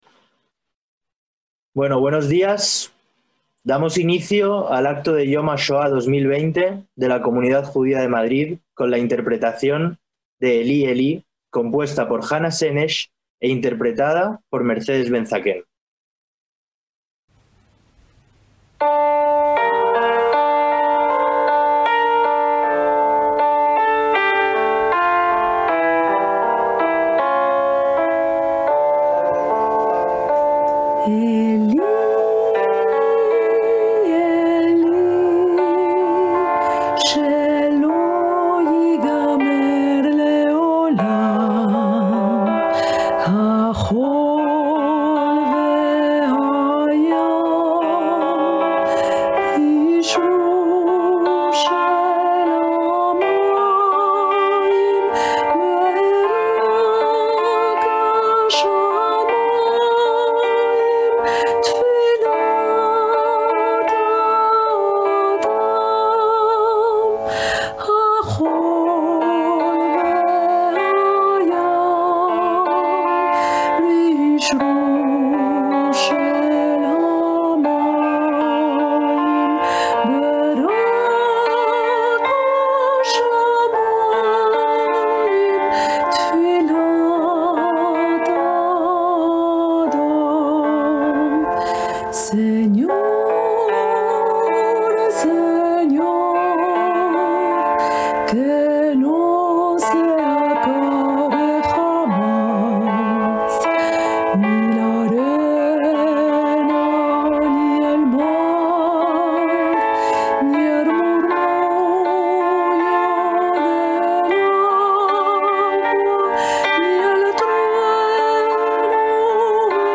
Acto online solemne de la Comunidad Judía de Madrid en recuerdo de la Shoá (21/4/2020)
la Comunidad Judía de Madrid llevó a cabo por medios telemáticos un acto en recuerdo del Holocausto
para concluir con la sirena que acompaña el minuto de silencio en honor de las víctimas del Holocausto.